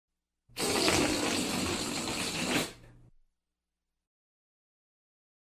Big Wet Fart Sound